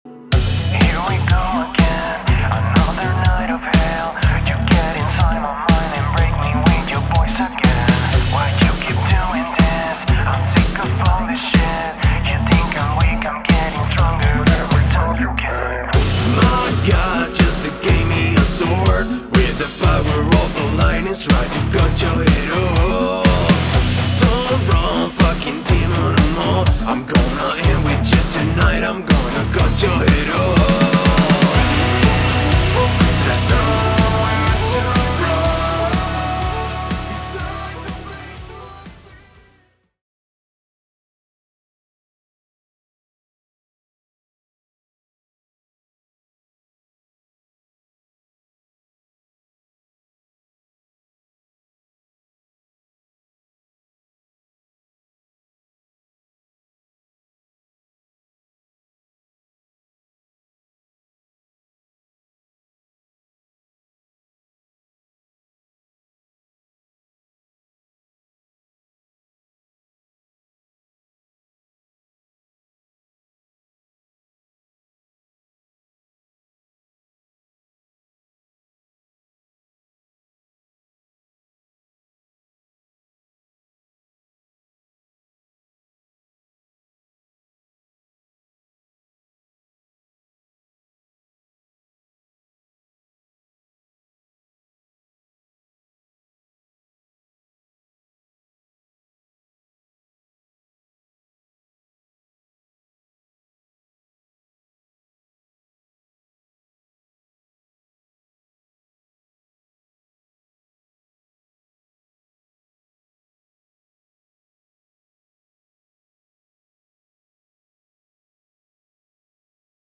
Género: Alternative / Metal.